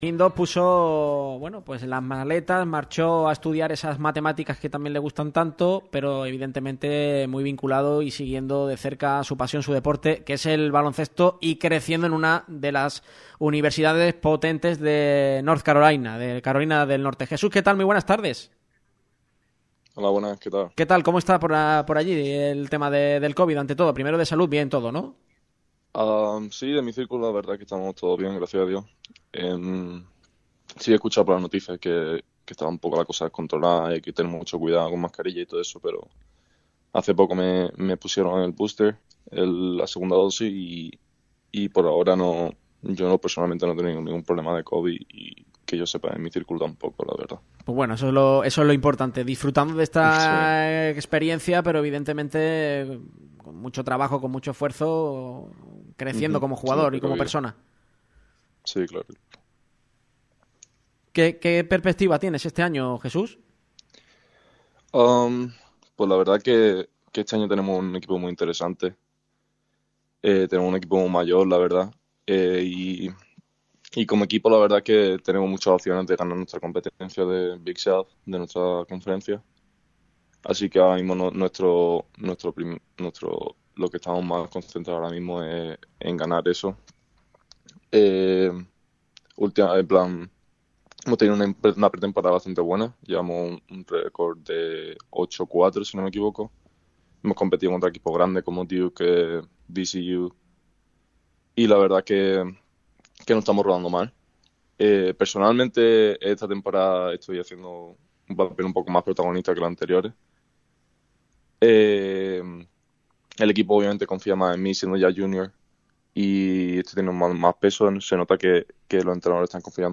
Hoy hemos hablado con él para que nos cuente su experiencia por Estados Unidos y las diferencias con el baloncesto europeo, entre otras cuestiones.